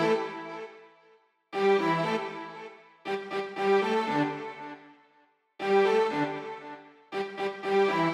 28 Strings PT2.wav